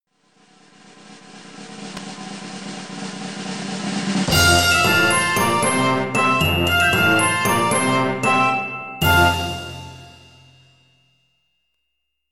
Success Resolution Video Game Fanfare Sound Effect with Drum Roll
alert bonus clip fanfare game happy level music sound effect free sound royalty free Music